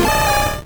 Cri de Machopeur dans Pokémon Rouge et Bleu.